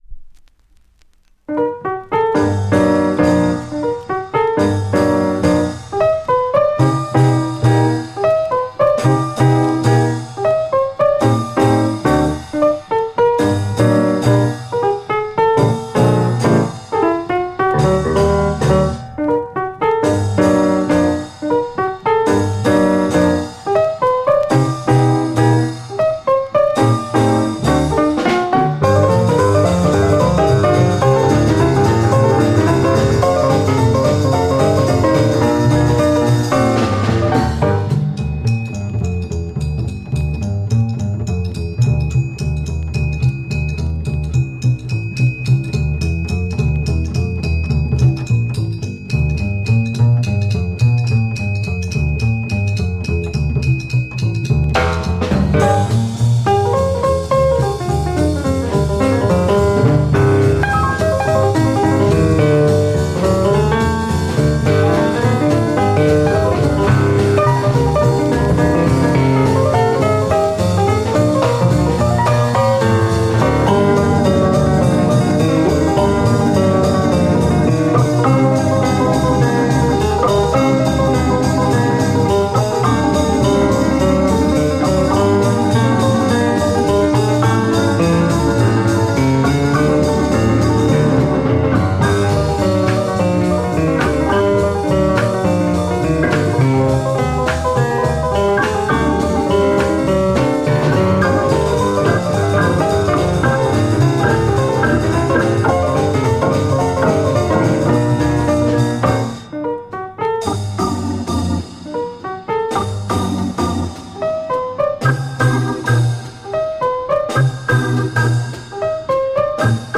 French pivate Jazz mod EP